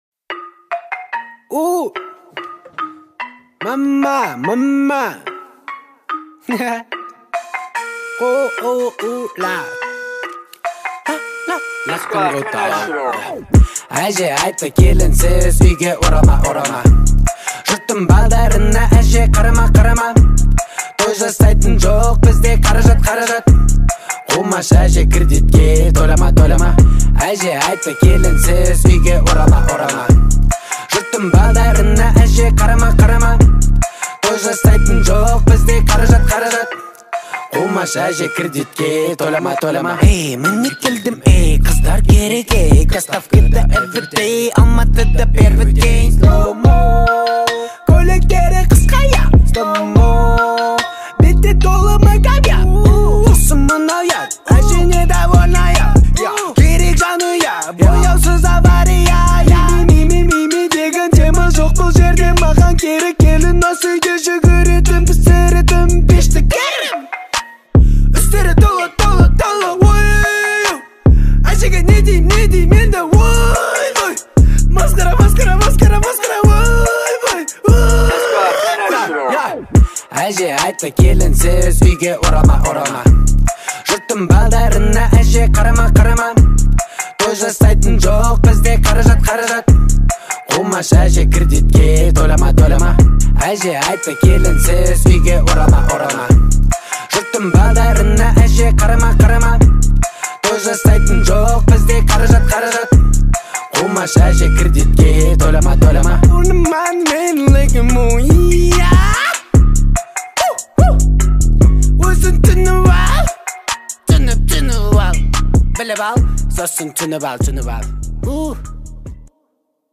отличается мелодичностью и выразительным вокалом